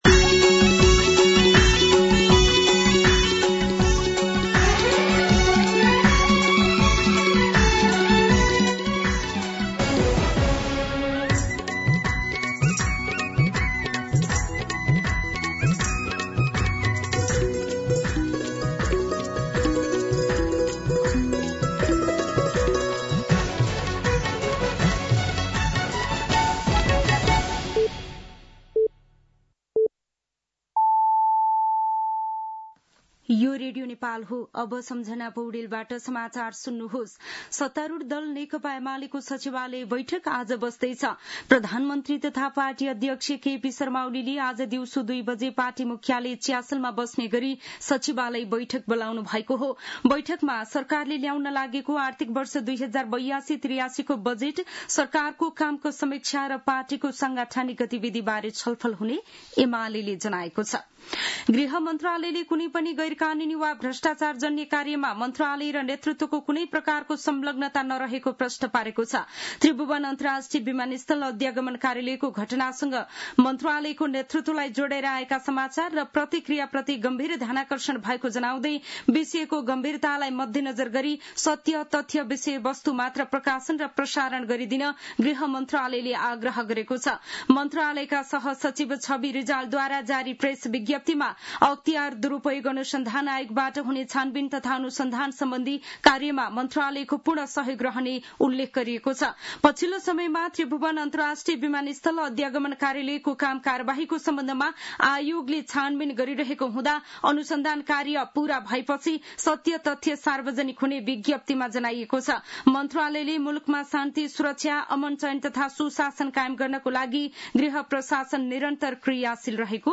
दिउँसो १ बजेको नेपाली समाचार : ११ जेठ , २०८२